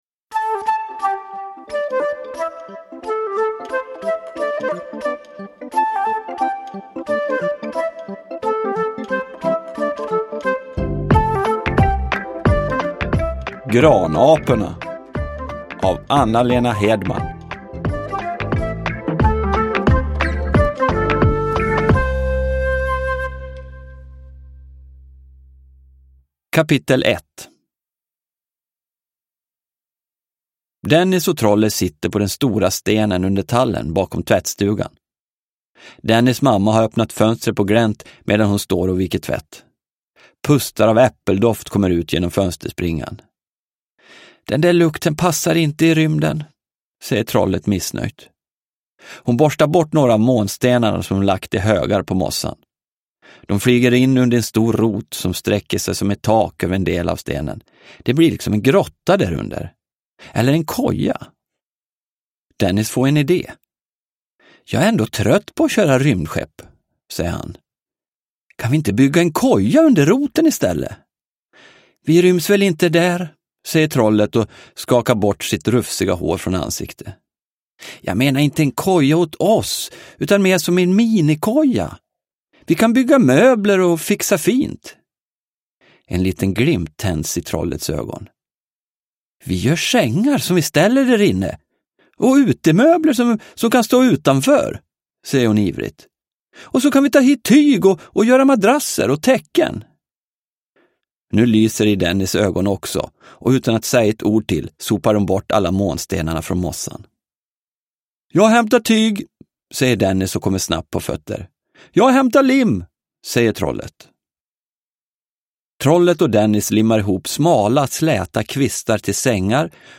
Granaporna – Ljudbok – Laddas ner